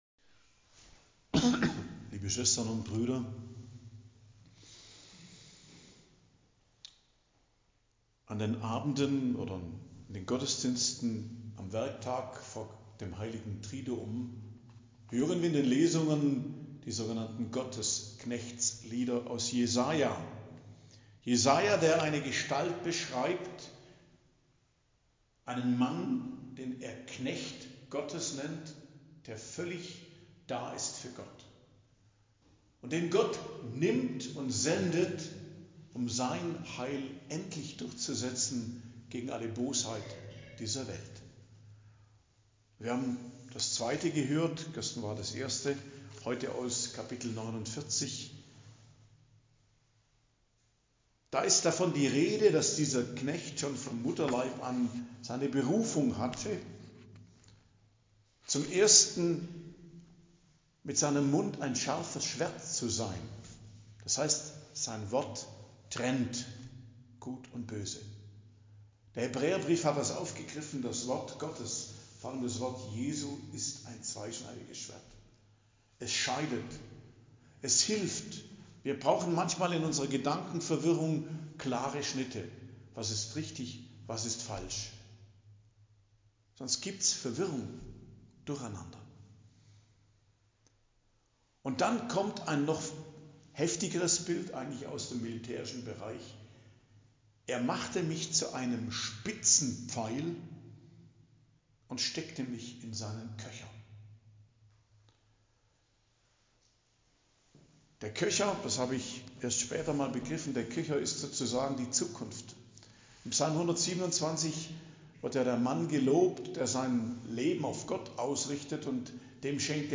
Predigt am Dienstag der Karwoche, 26.04.2024 ~ Geistliches Zentrum Kloster Heiligkreuztal Podcast